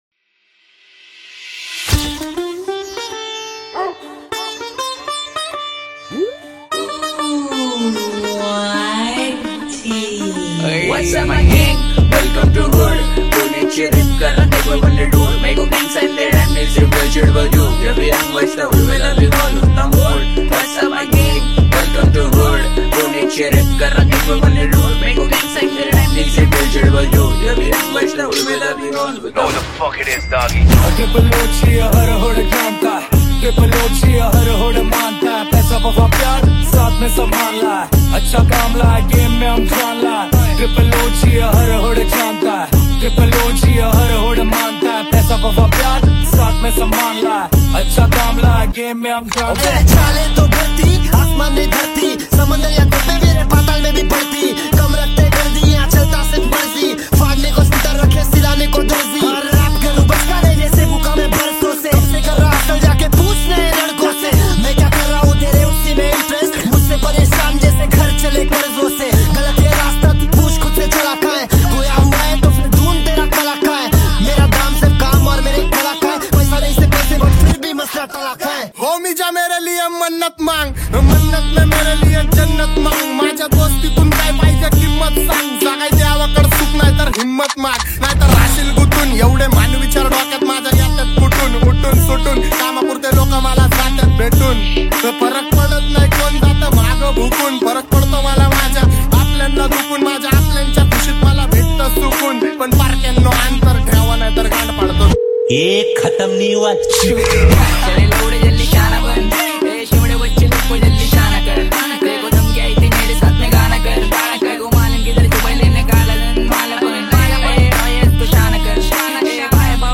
Releted Files Of DJ Remix